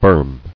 [berm]